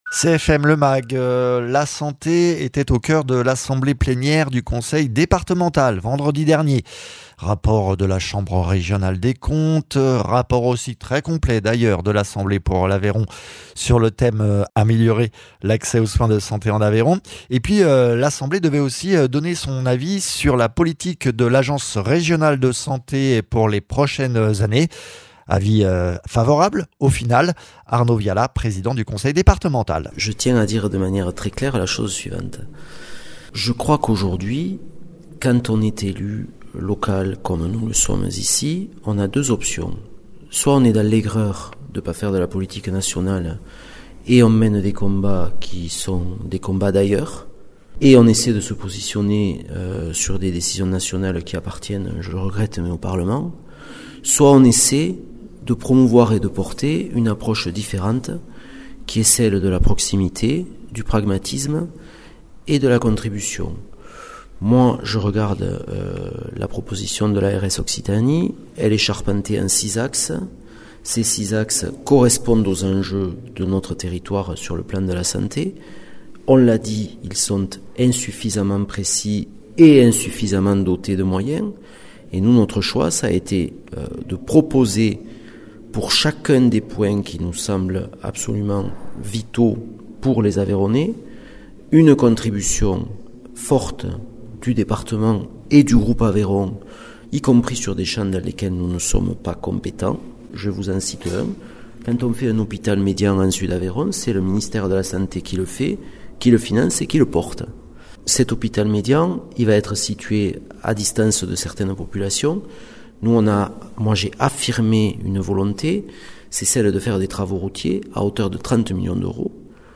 Interviews
Invité(s) : Arnaud Viala, Président du conseil départemental de l’Aveyron